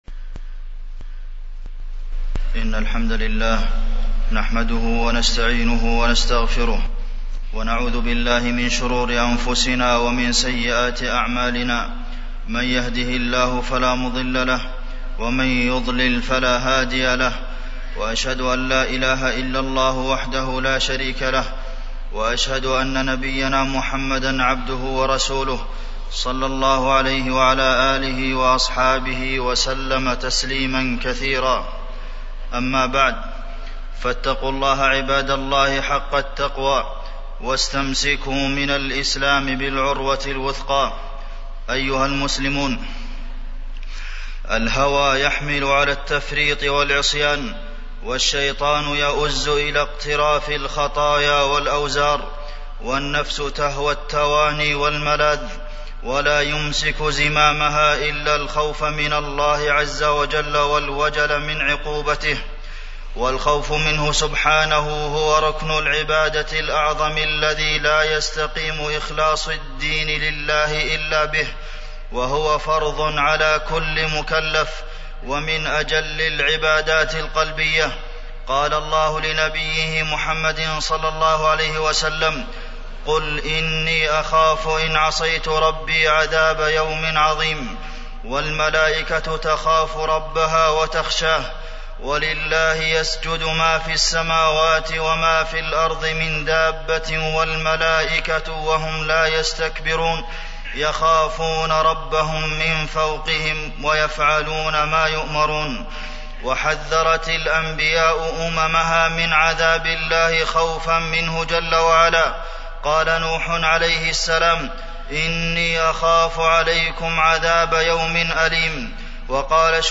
تاريخ النشر ٢١ ربيع الثاني ١٤٢٧ هـ المكان: المسجد النبوي الشيخ: فضيلة الشيخ د. عبدالمحسن بن محمد القاسم فضيلة الشيخ د. عبدالمحسن بن محمد القاسم الخوف والرجاء